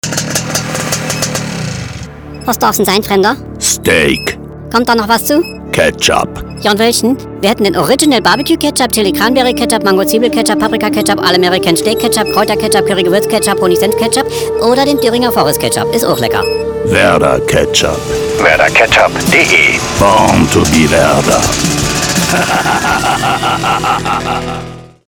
Improvisierte Radiospots Galoma Radiowerbung Audio
Und hier sind einige Beispiele unserer Impro-Spots, die sowohl unseren Kunden, als auch deren Zielgruppen gefallen: